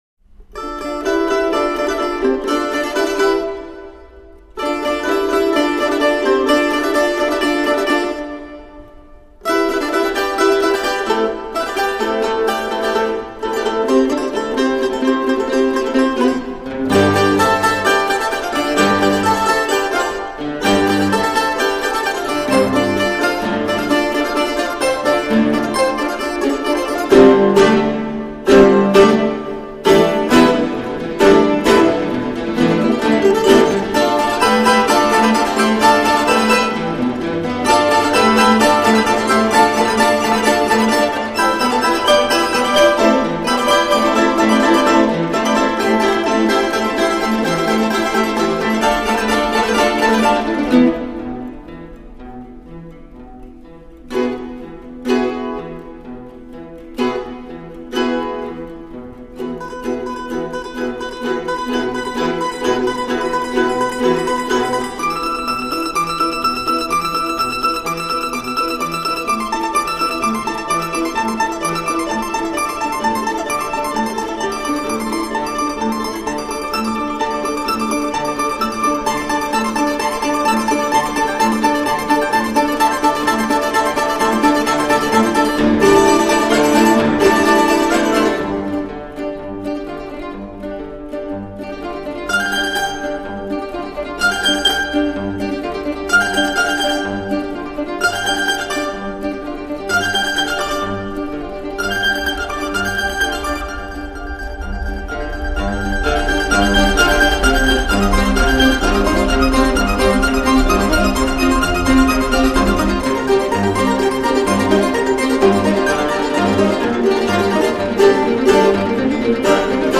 音乐类型: 民乐
阮族重奏
这种民乐单一弹拨乐器的合奏比较少见，特别是阮这种乐器，所以要收藏一下。